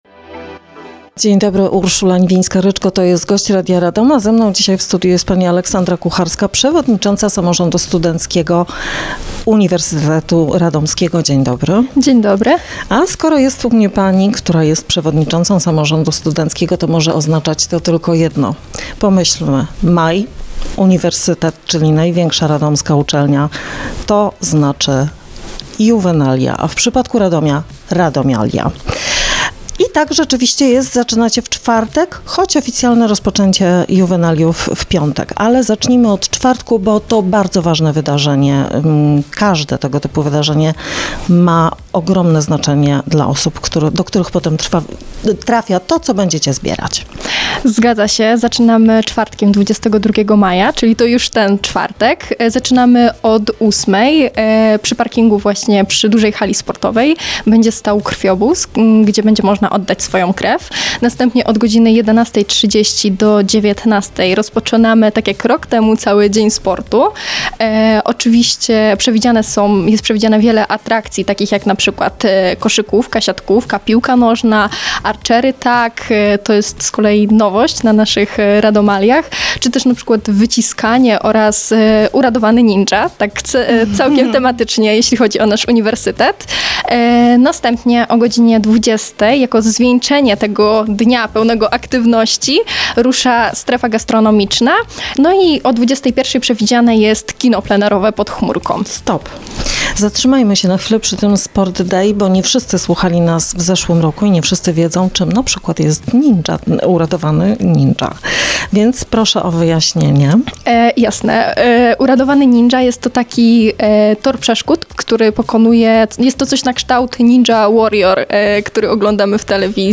Rozmowa dostępna jest również na facebookowym profilu Radia Radom: